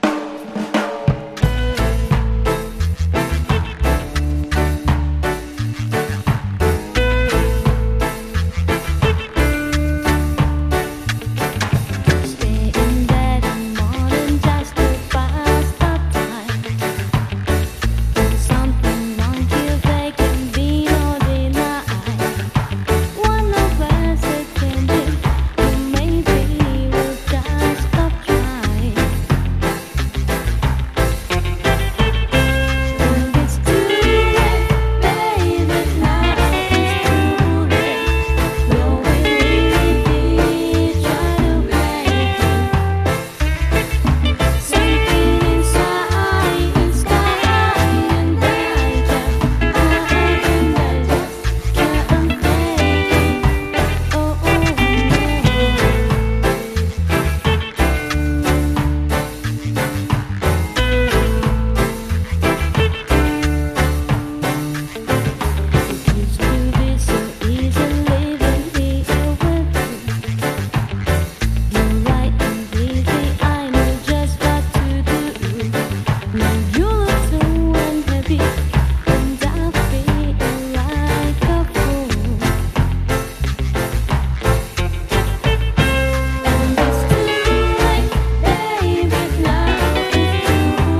カヴァー